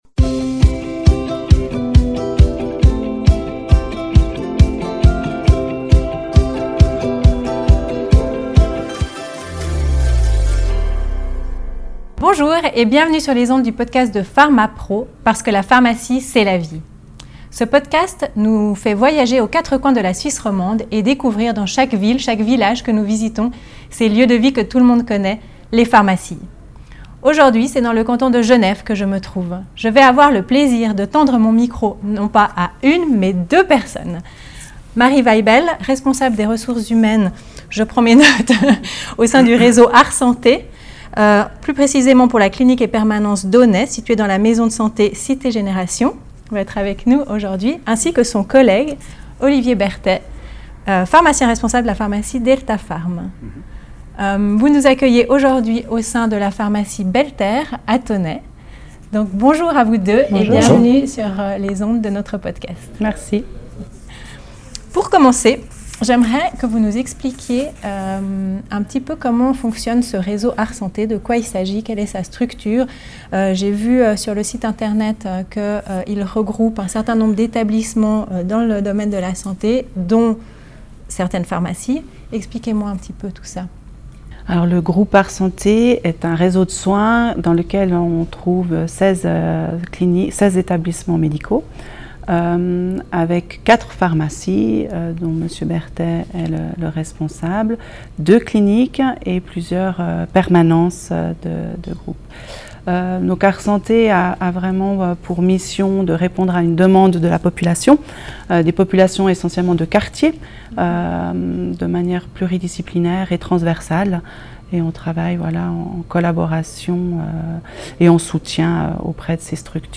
interview croisée au sein d'un réseau foncièrement humain